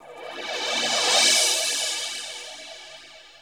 Tech Step 2 Cymbal.WAV